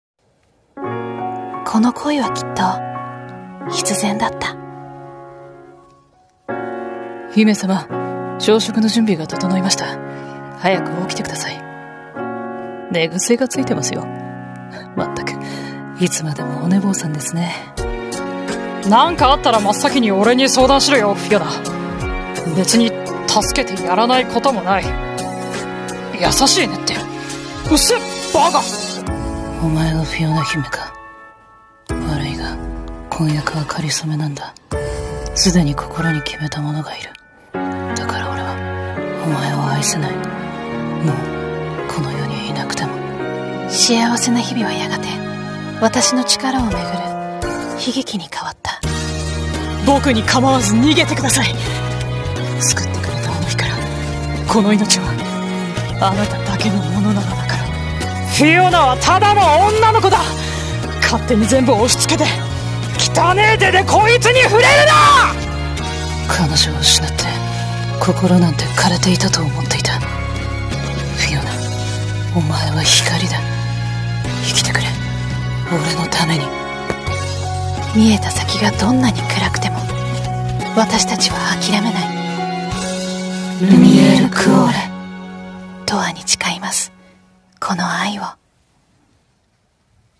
乙女ゲームCM風声劇【Lumiere Cuore